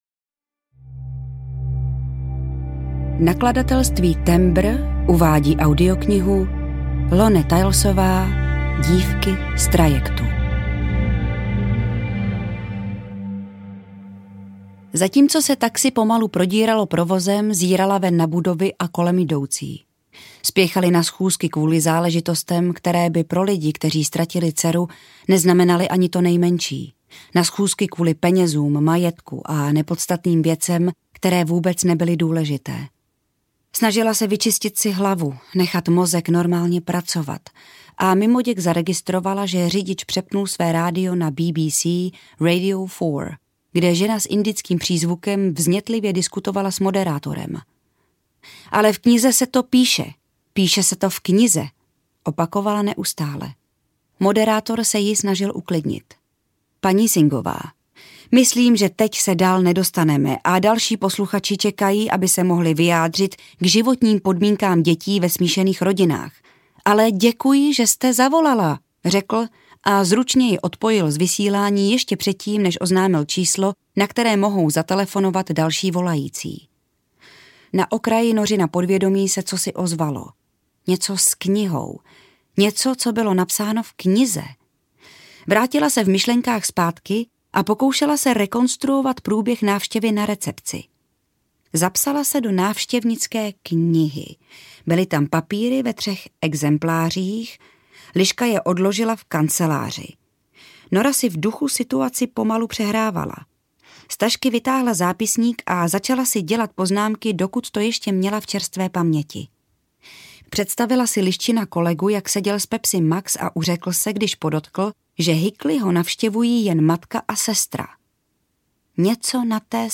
Audio knihaDívky z trajektu
Ukázka z knihy